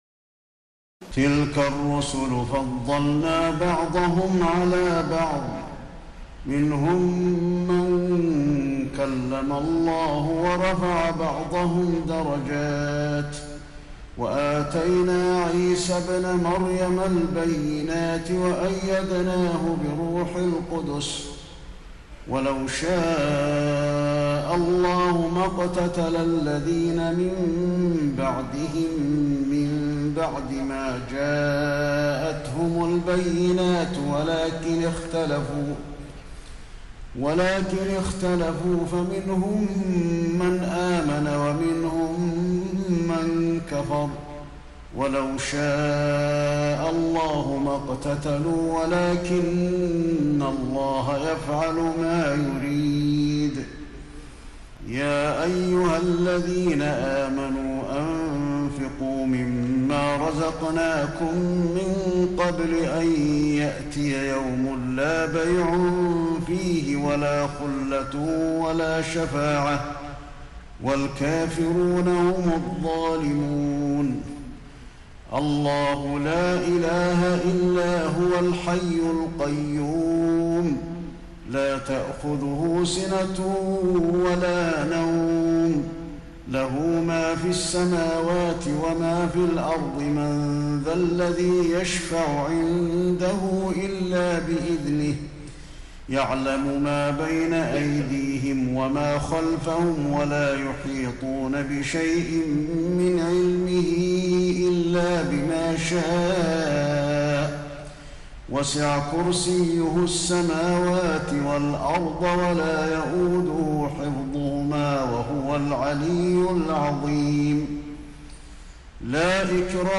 تراويح الليلة الثالثة رمضان 1432هـ من سورتي البقرة (253-286) و آل عمران (1-17) Taraweeh 3st night Ramadan 1432H from Surah Al-Baqara and Surah Aal-i-Imraan > تراويح الحرم النبوي عام 1432 🕌 > التراويح - تلاوات الحرمين